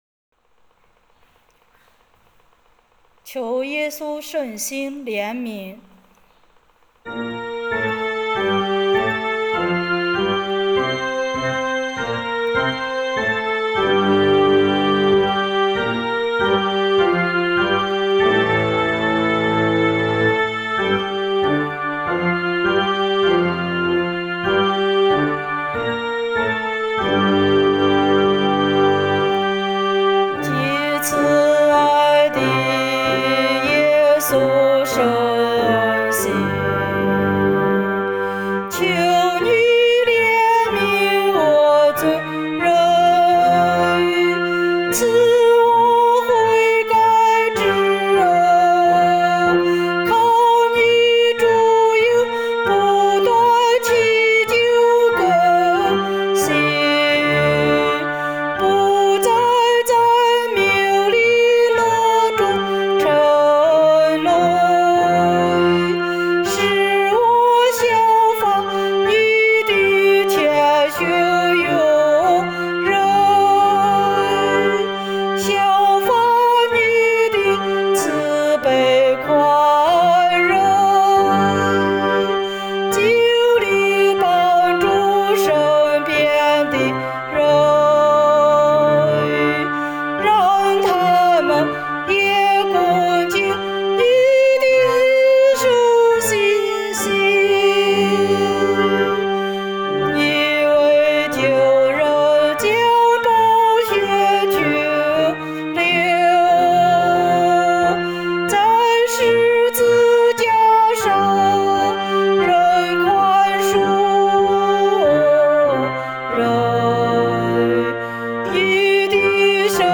【原创圣歌】|《求耶稣圣心怜悯